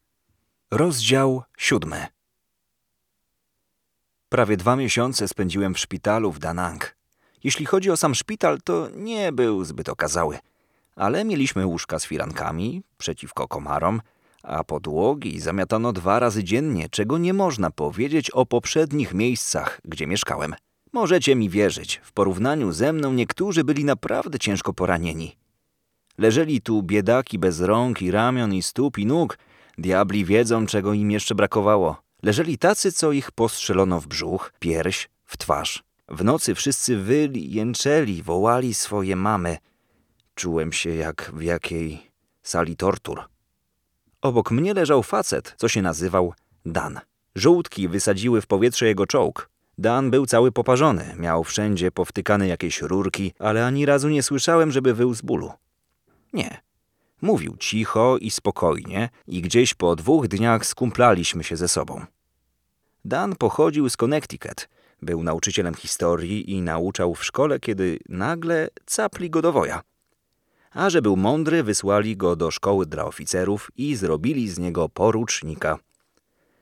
Male 20-30 lat
Low, resonant voice with good interpretation, suitable for audiobooks, commercials and dubbing alike.
Narracja